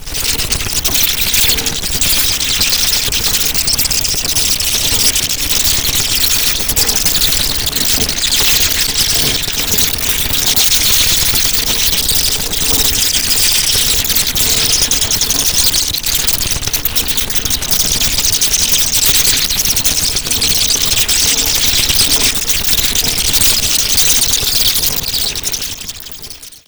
bats_ambix.wav